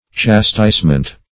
Chastisement \Chas"tise*ment\, n. [From Chastise.]